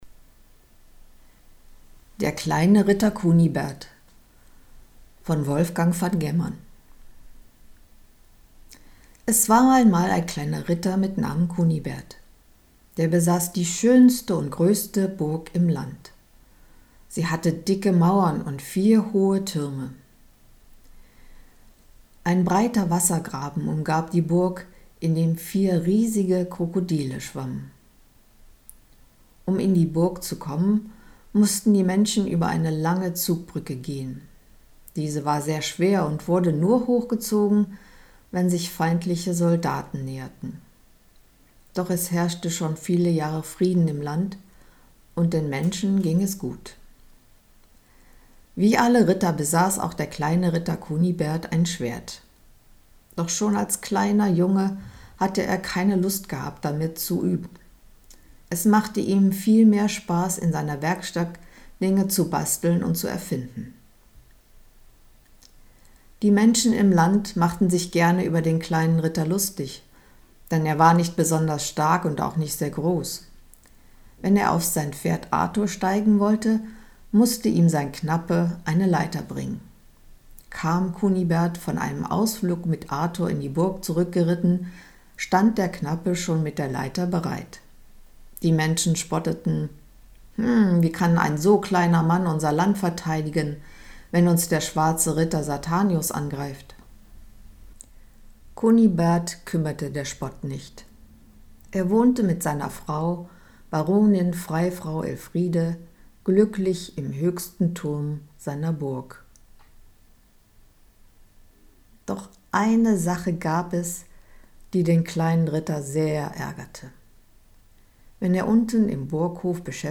Wir lesen Ihnen vor!